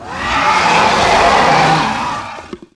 primalscream.wav